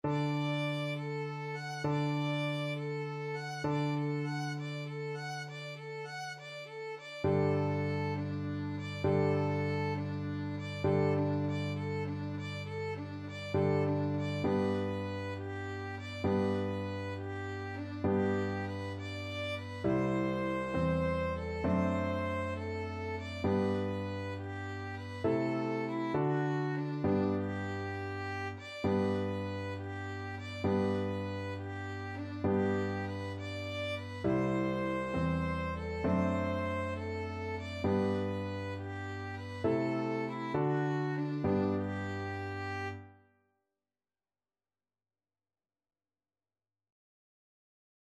6/8 (View more 6/8 Music)
Allegretto
Classical (View more Classical Violin Music)